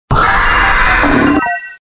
Cri de Palkia dans Pokémon Diamant et Perle.